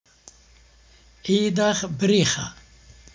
Merry Christmas greeting to a woman.